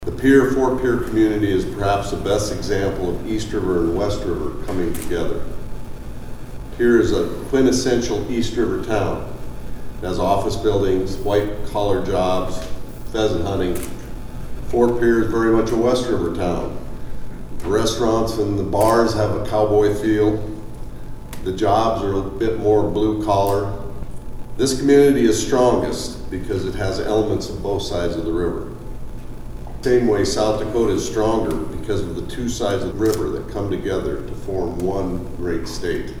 Governor Larry Rhoden says the bridge literally connects South Dakota…